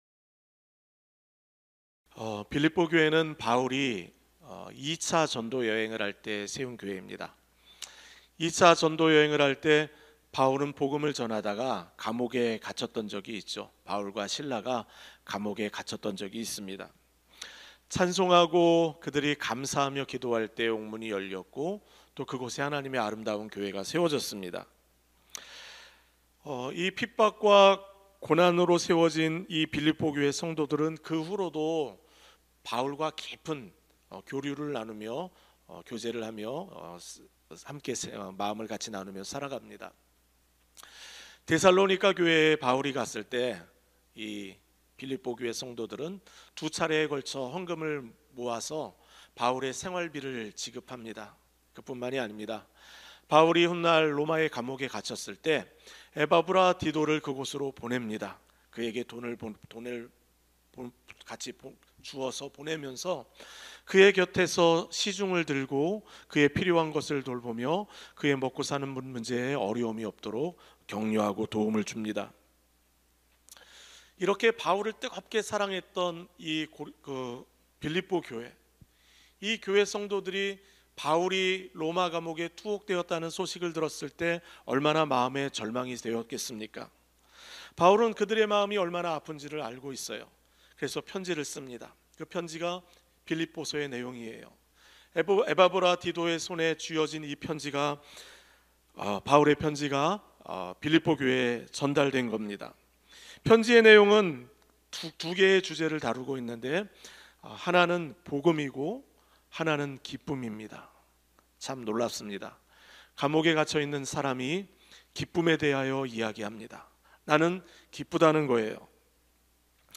예배: 평일 새벽